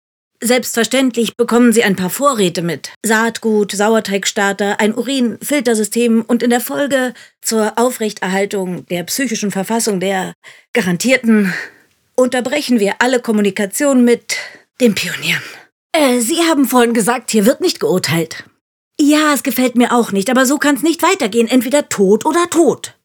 markant, dunkel, sonor, souverän, plakativ
Mittel minus (25-45)
Berlinerisch
Lip-Sync (Synchron)